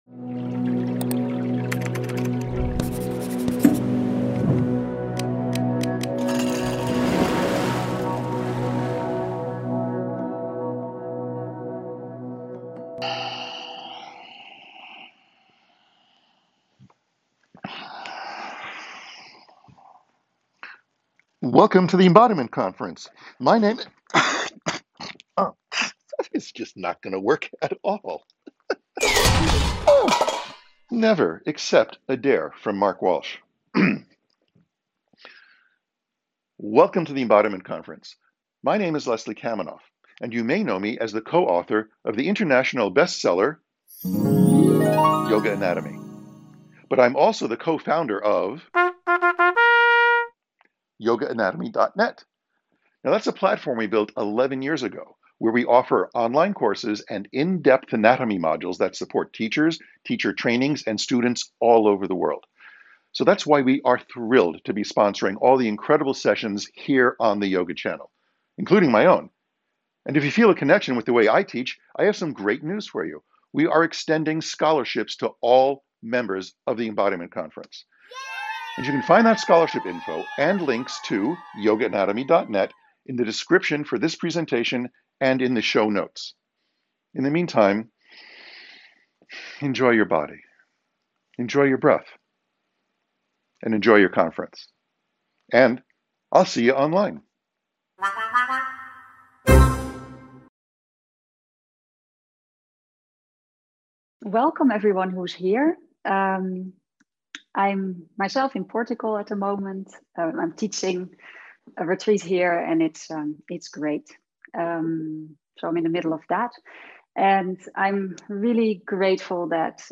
A Welcoming Practice Intermediate understanding Some standing/ movement Likely neutral Some meditation and some gentle movement exploration. We start with a meditation that invites us to gently pour our awareness in our physical form and shape and experience the body as a whole.